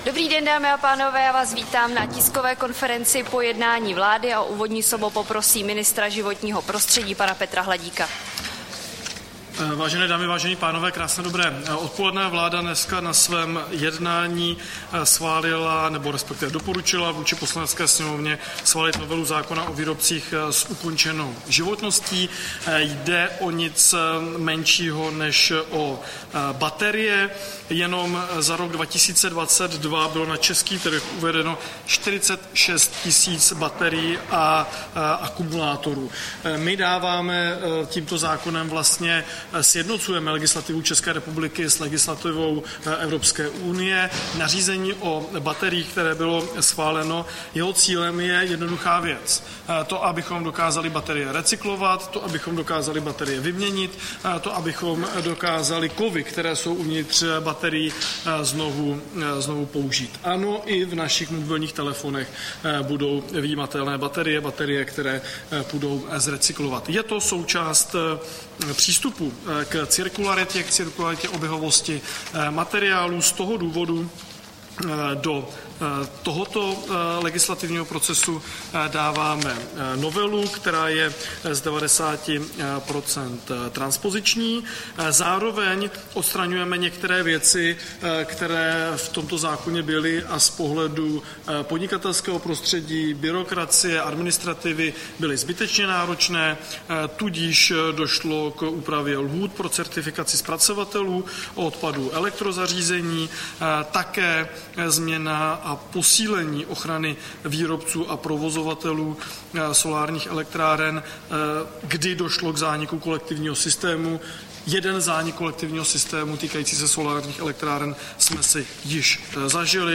Tisková konference po jednání vlády, 26. března 2025